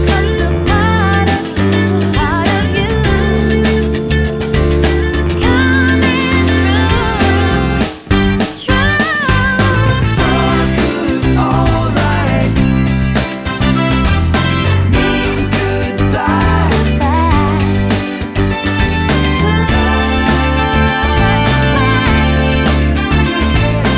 Without Female Lead Soundtracks 3:31 Buy £1.50